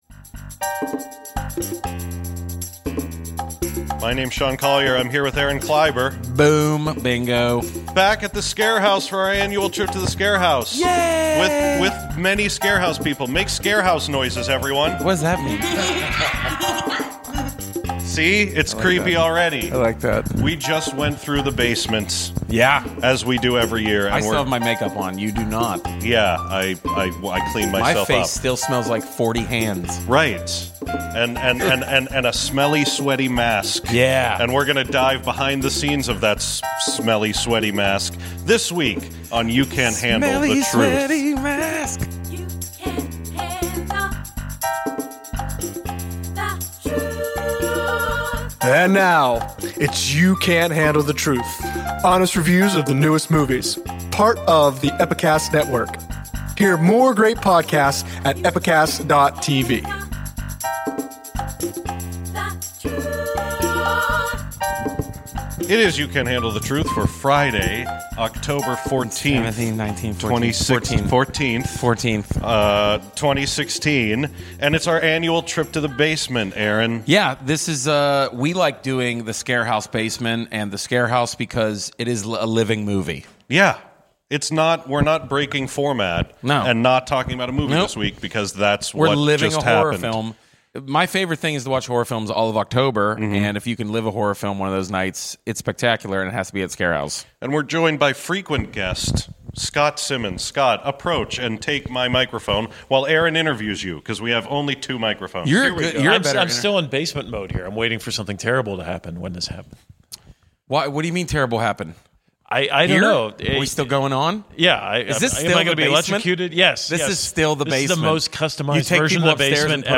YCHTT #163 — Live from ScareHouse 2016